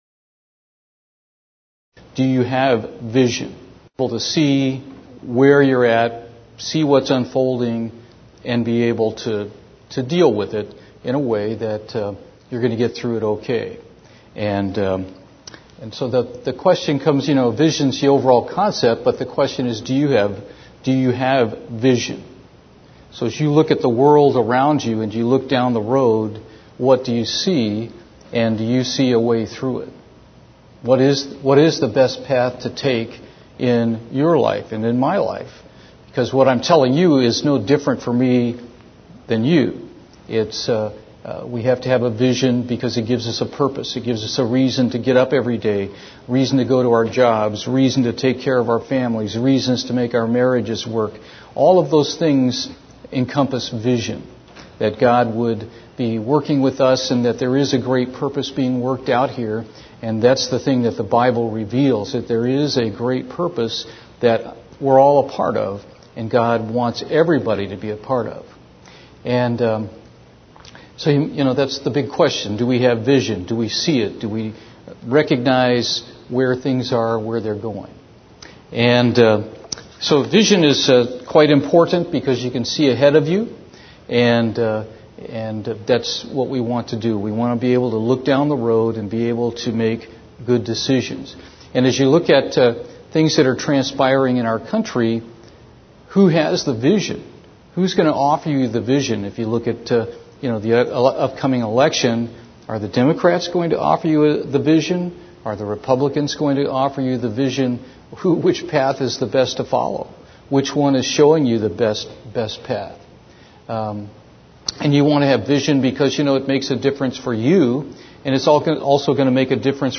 Sermons
Given in Houston, TX